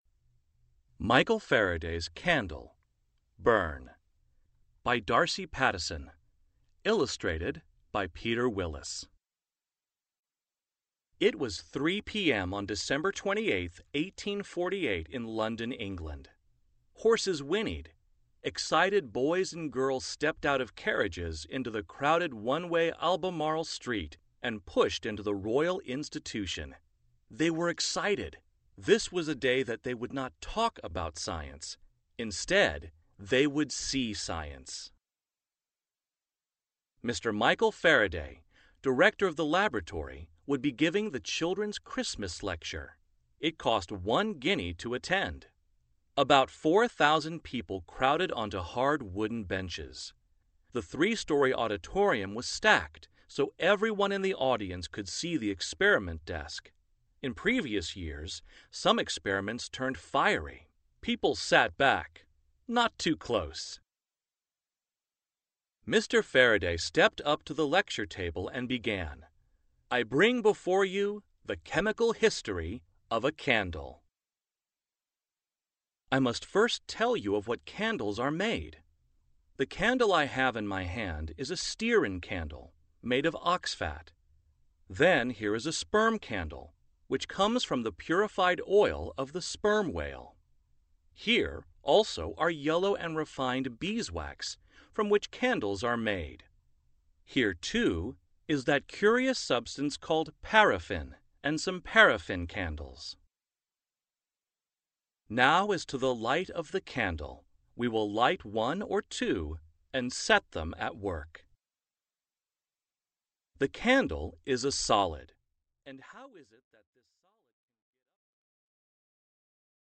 Audiobook - Burn